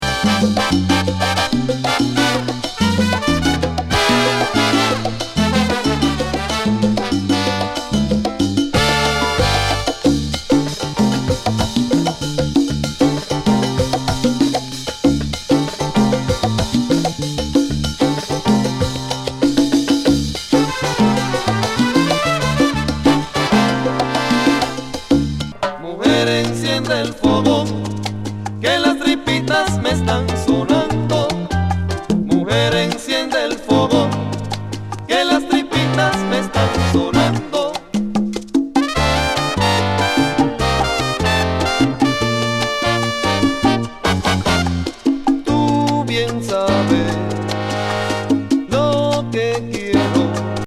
ハードなギターが暴れまわるモンド？